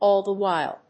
áll the whíle